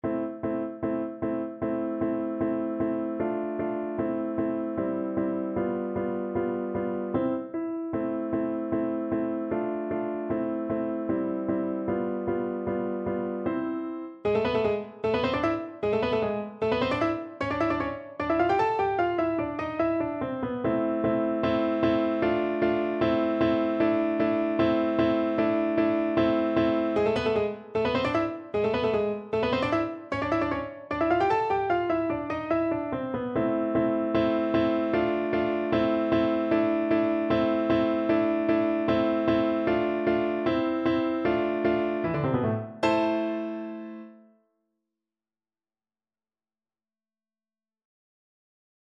Play (or use space bar on your keyboard) Pause Music Playalong - Piano Accompaniment Playalong Band Accompaniment not yet available transpose reset tempo print settings full screen
A minor (Sounding Pitch) (View more A minor Music for Bassoon )
Allegro scherzando (=152) (View more music marked Allegro)
2/4 (View more 2/4 Music)
Classical (View more Classical Bassoon Music)